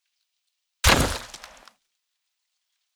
Hunting Bow Headshot